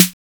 808 FAT SNR.wav